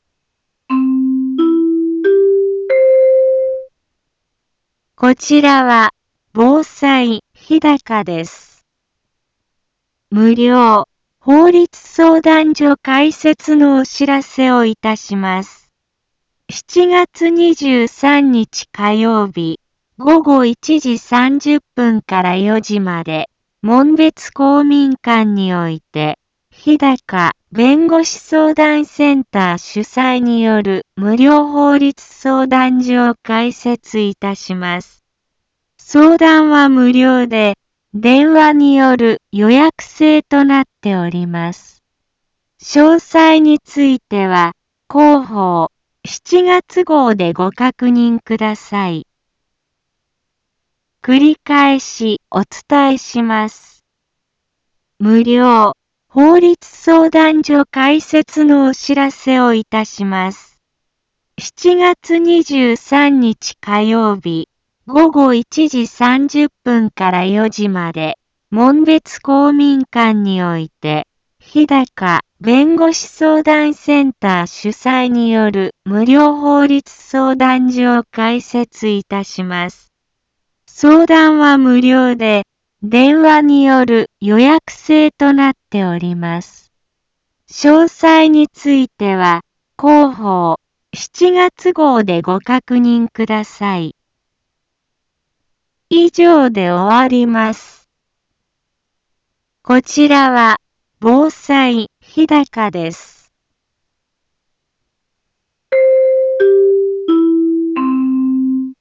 一般放送情報
Back Home 一般放送情報 音声放送 再生 一般放送情報 登録日時：2024-07-19 10:03:49 タイトル：無料法律相談会のお知らせ インフォメーション： 無料法律相談所開設のお知らせをいたします。 7月23日火曜日午後1時30分から4時まで、門別公民館において、ひだか弁護士相談センター主催による無料法律相談所を開設いたします。